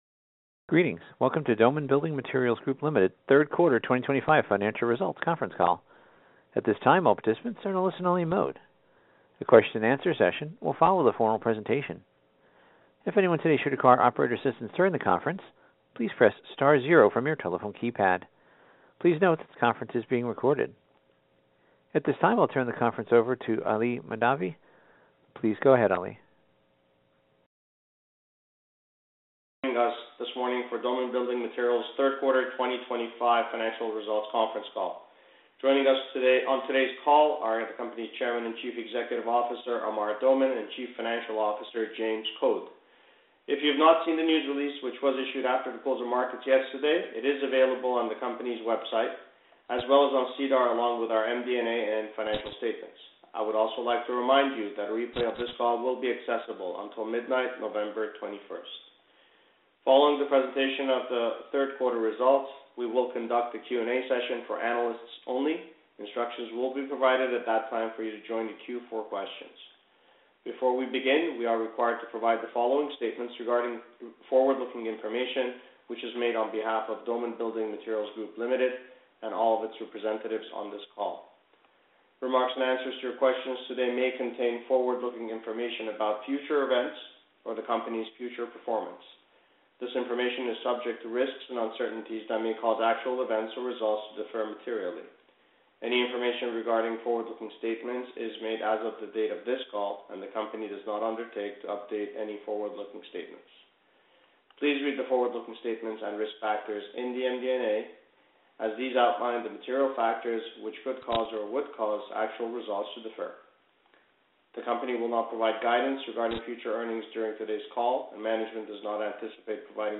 Doman_Analyst_Call_Q3_2025.mp3